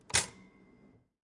烤面包机
描述：流行的烤面包机。